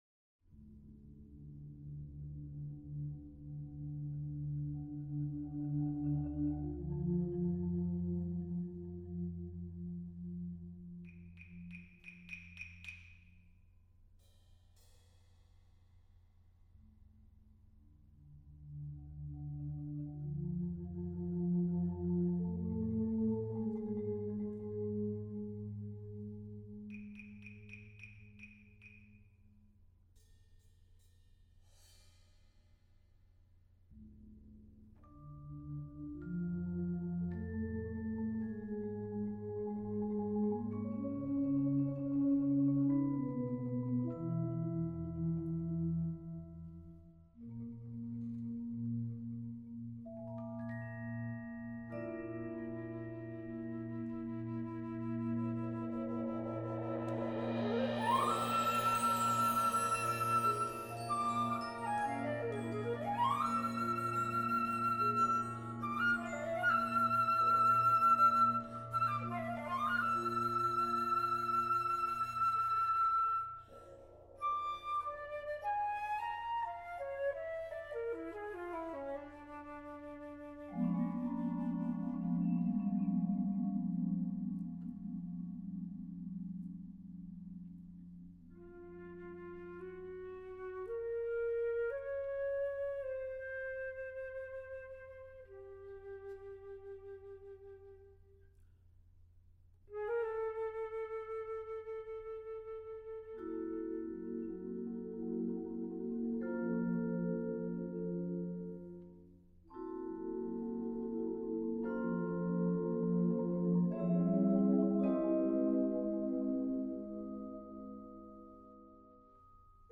Genre: Solo Flute + Percussion Ensemble
Solo Piccolo/Flute
Player 2: Vibraphone 1, Small Metal Wind Chimes, bass bow
Player 7: Celeste (may substitute Synthesizer)